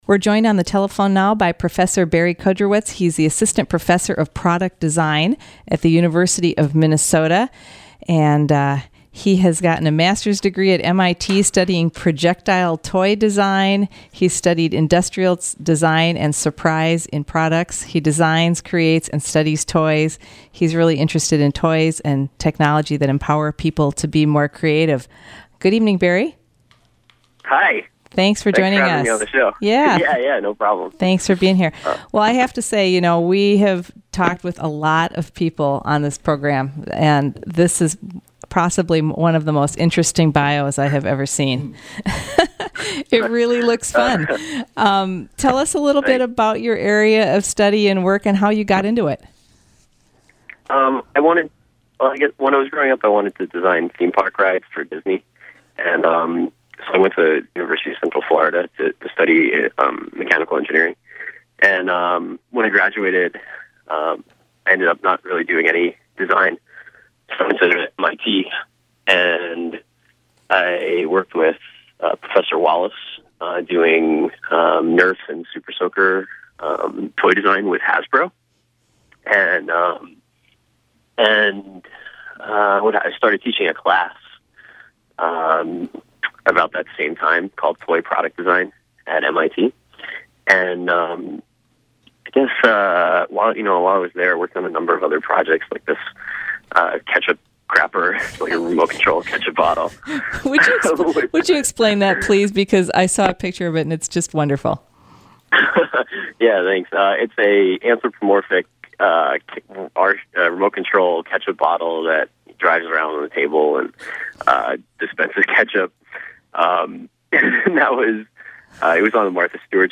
chatted